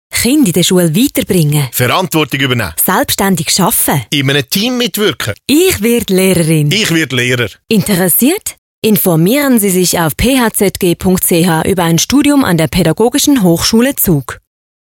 Radiospot PH Zug